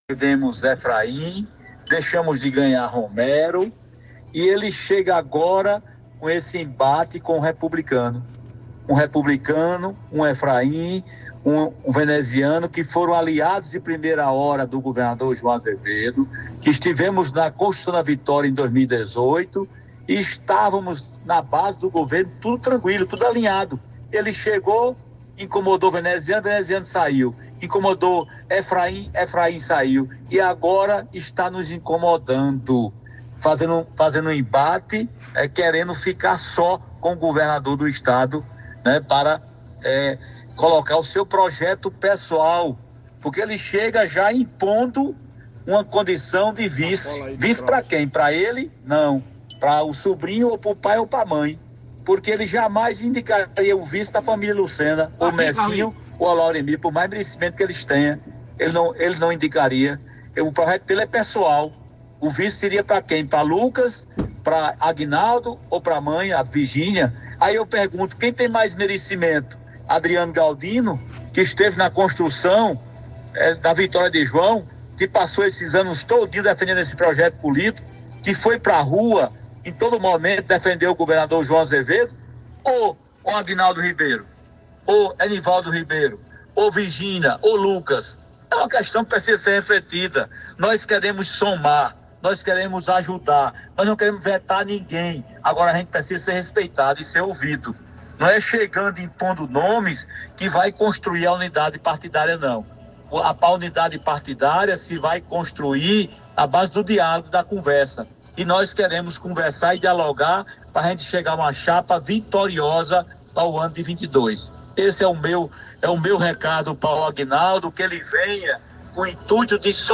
A declaração é do presidente da Assembleia Legislativa da Paraíba, Adriano Galdino, durante entrevista a uma emissora de rádio de João Pessoa, na tarde desta sexta-feira (17).